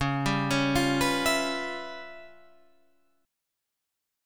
C#7#9b5 chord